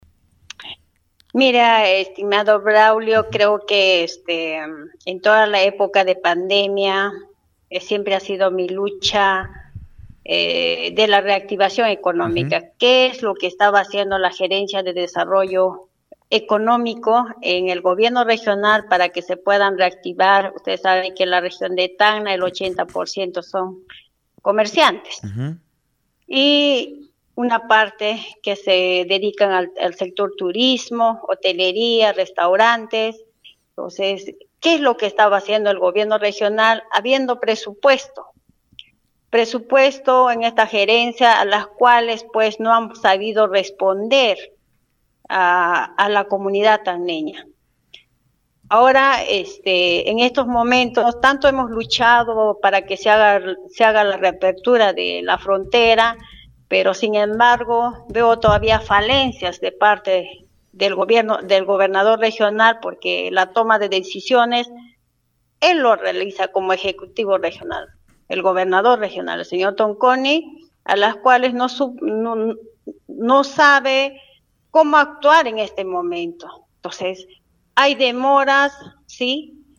La consejera regional Luz Huancapaza, declaró en entrevista a Radio Uno, que la región presenta el 80% de población dedicada al comercio, de los cuales el 20% se destina al sector turismo.